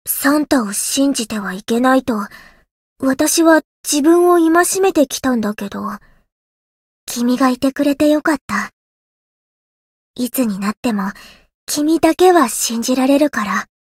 灵魂潮汐-西勒诺斯-圣诞节（送礼语音）.ogg